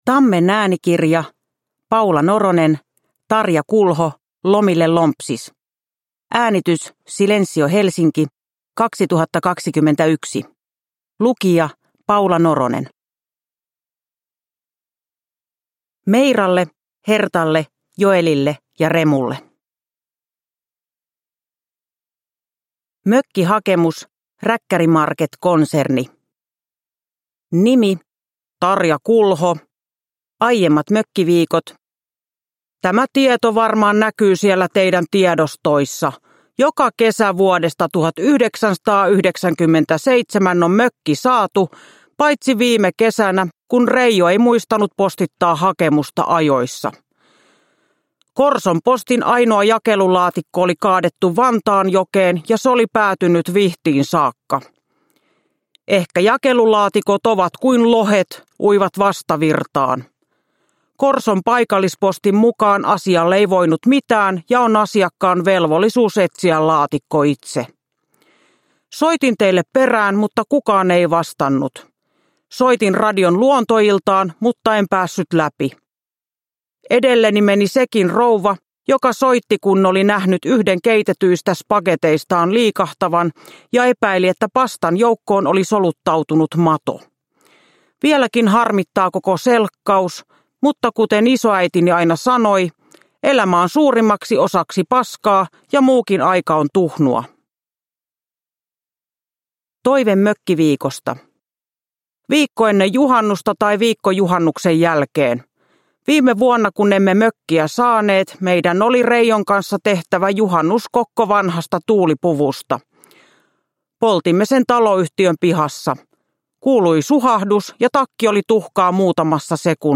Tarja Kulho ? Lomille lompsis – Ljudbok